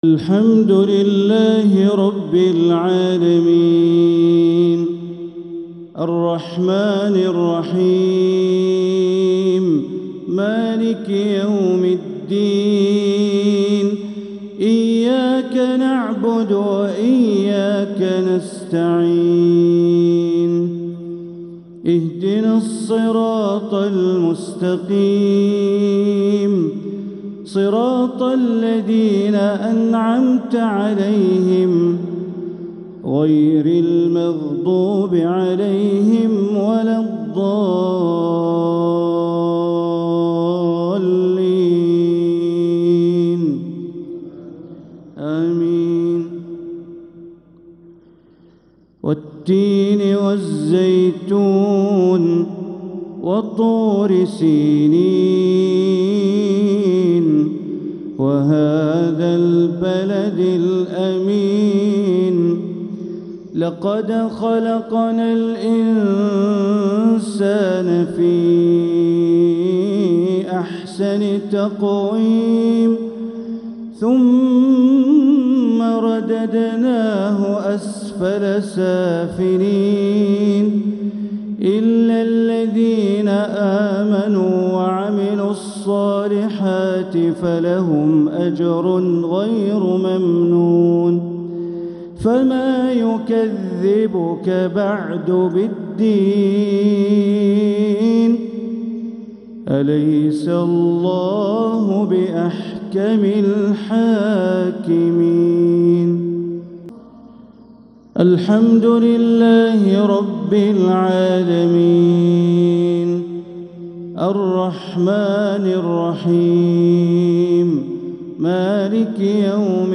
تلاوة جميلة لسورتي التين والزلزلة | مغرب الأحد 8-5-1446هـ > 1446هـ > الفروض - تلاوات بندر بليلة